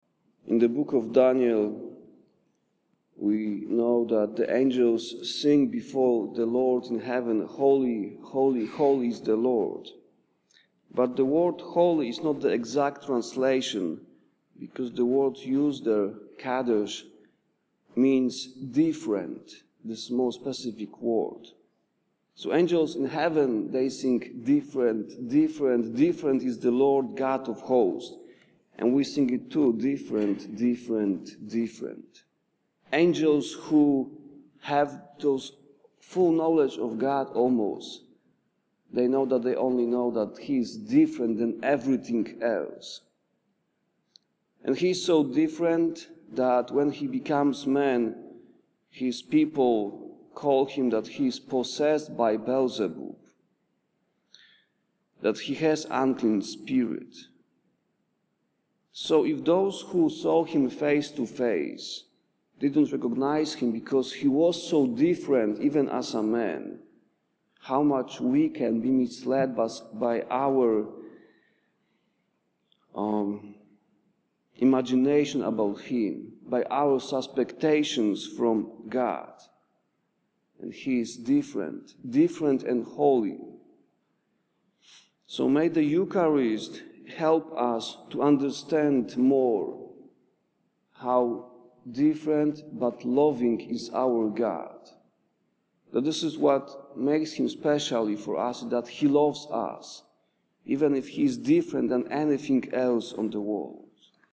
early Morning Homily : )